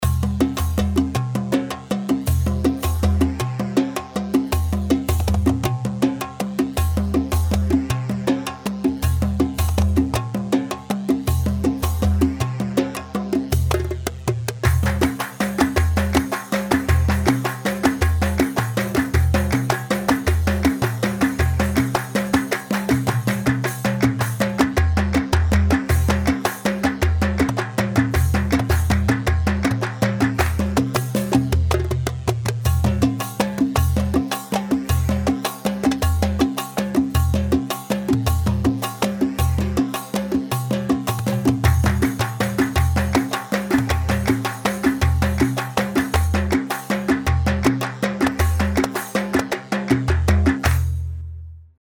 Yemeni
Sharh Yemeni 3/4 160 شرح يمني
Shareh-Yamani-3-4-160.mp3